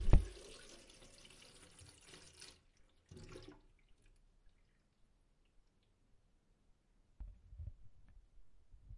水 " 关掉水龙头
描述：关掉水龙头，听到水从下水道漱口。 记录在Zoom H1上。
标签： 自来水 排水 漱口
声道立体声